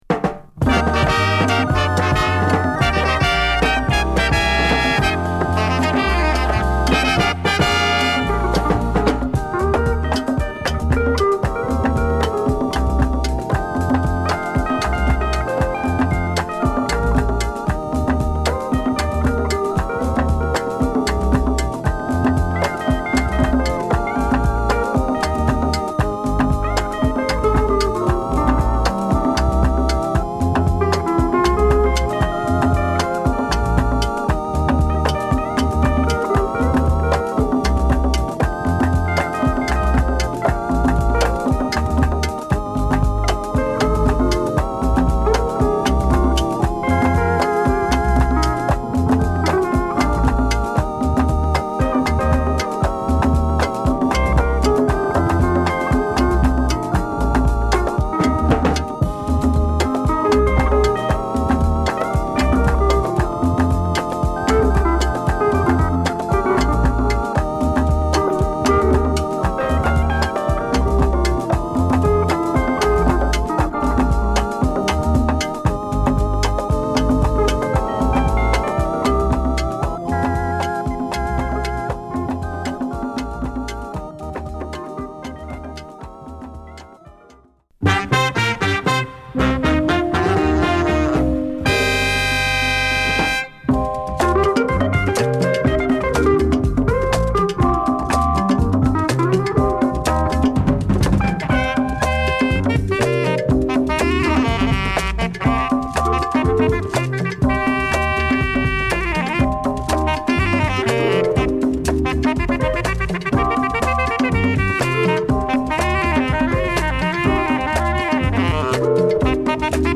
カリブ～ラテン、ハイライフ的な温かな高揚感と濃密な演奏で